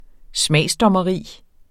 Udtale [ -dʌmʌˌʁiˀ ]